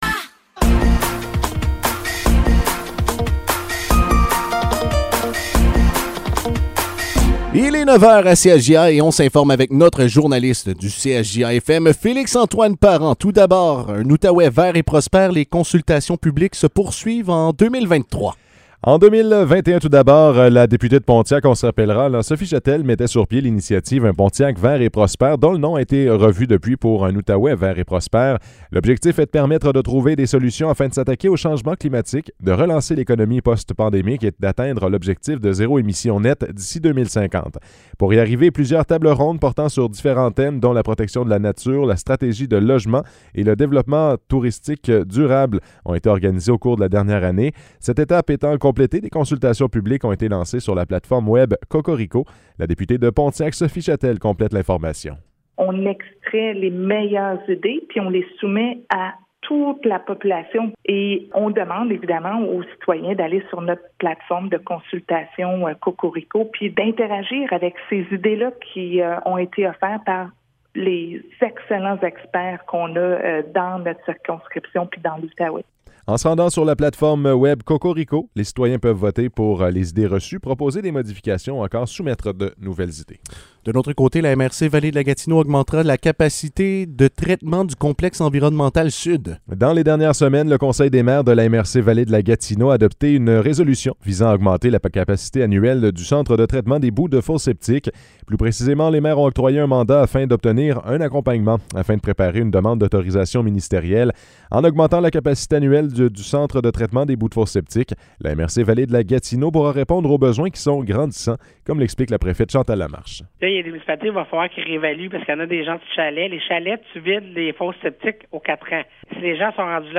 Nouvelles locales - 29 décembre 2022 - 9 h